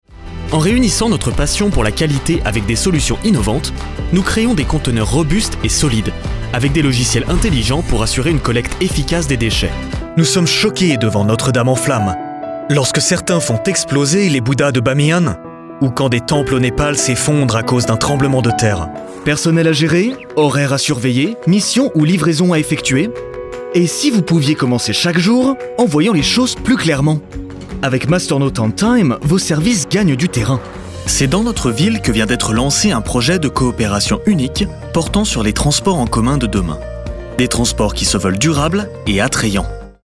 Natural, Versátil, Seguro, Amable, Empresarial
Corporativo
Su voz natural, de tono medio-grave, suena profesional y confiable, pero también cálida y amigable, ideal para contenido corporativo y educativo.